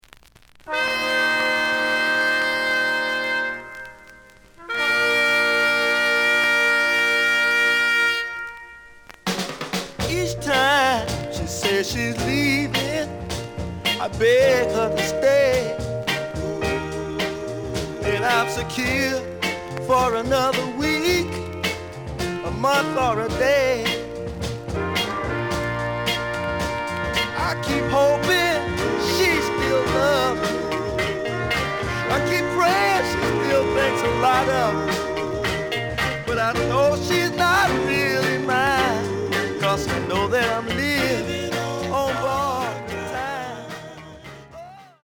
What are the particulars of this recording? The audio sample is recorded from the actual item. B side plays good.)